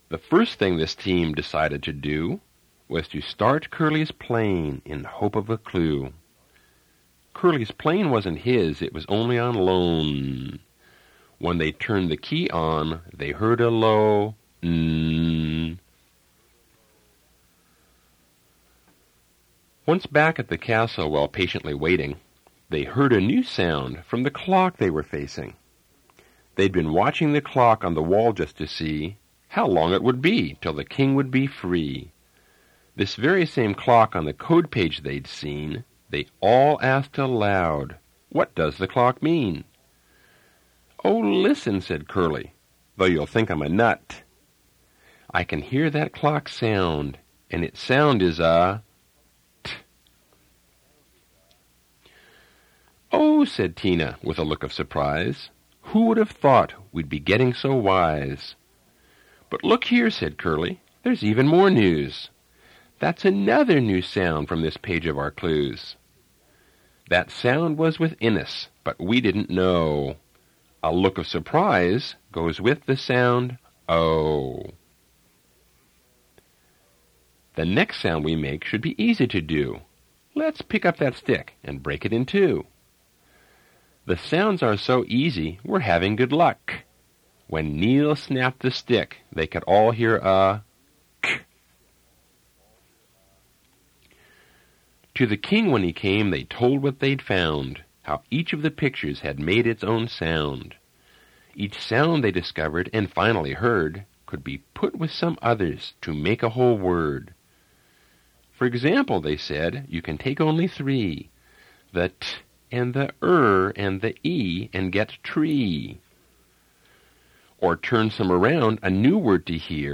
Reading of Dekodiphukan